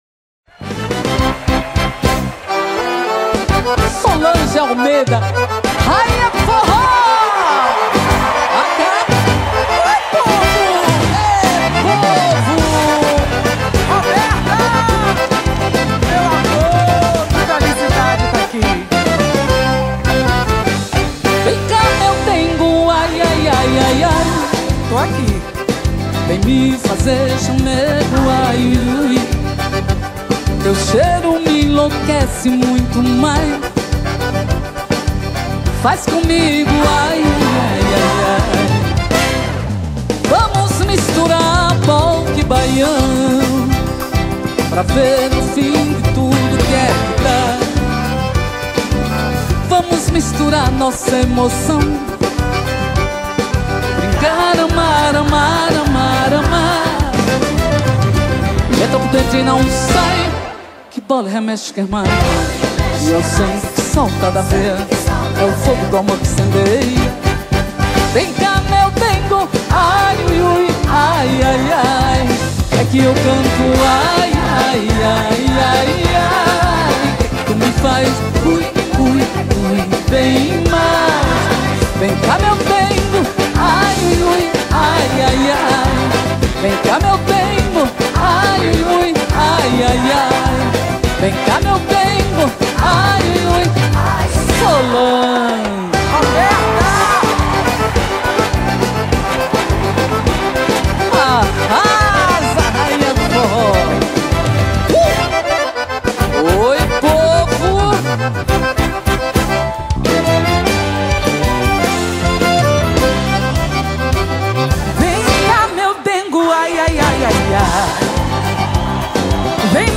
Sertanejas Para Ouvir: Clik na Musica.